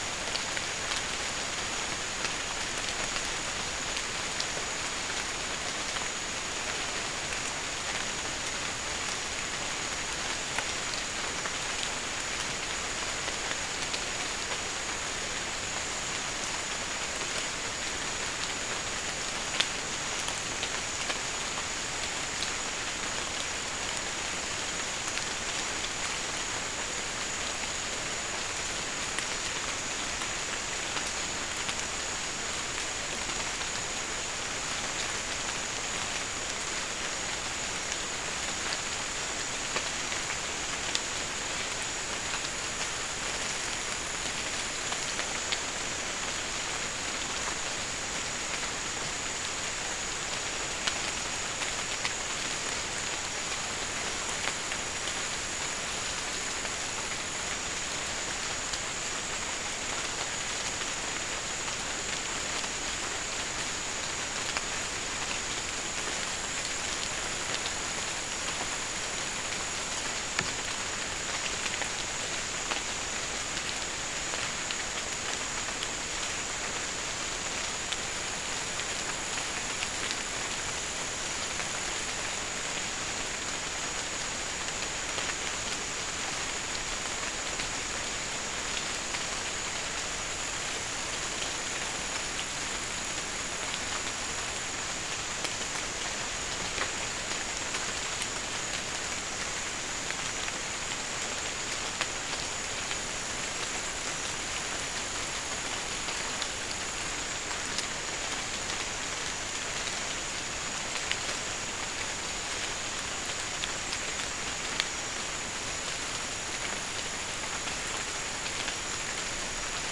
Non-specimen recording: Soundscape Recording Location: South America: Guyana: Rock Landing: 1
Recorder: SM3